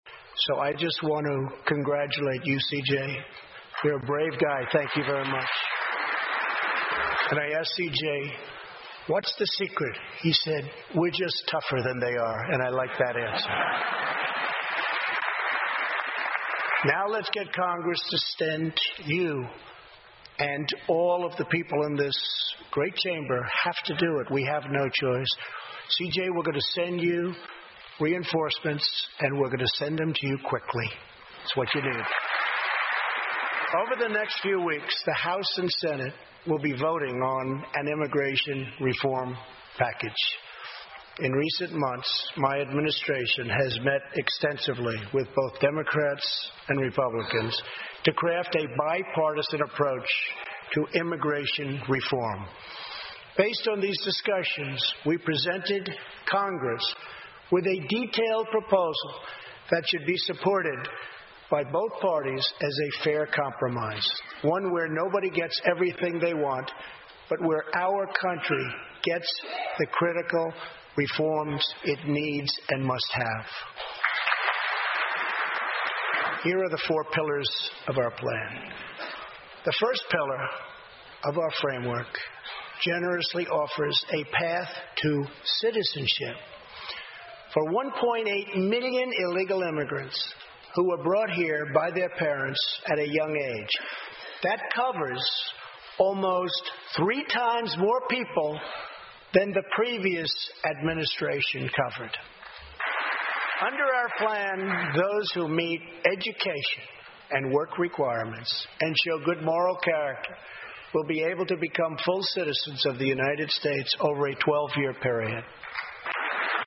欧美名人演讲 第108期:美国总统川普首次国情咨文演讲(15) 听力文件下载—在线英语听力室